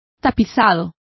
Complete with pronunciation of the translation of upholstery.